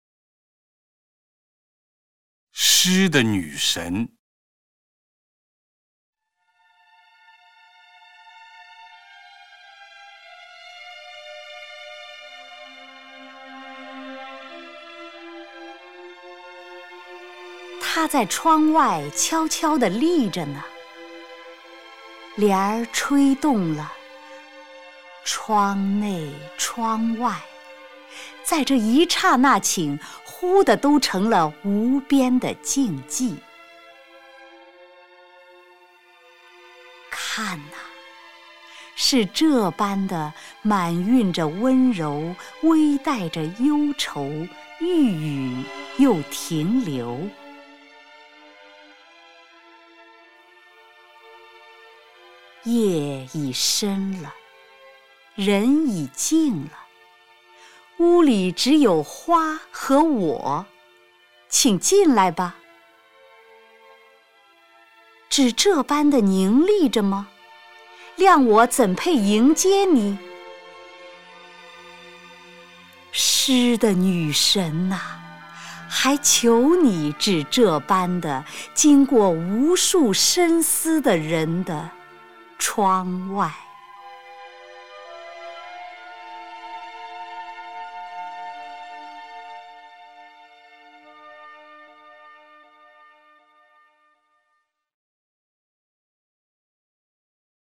[2/8/2010]丁建华配乐朗诵冰心诗作《诗的女神》（192K MP3）
朗诵 丁建华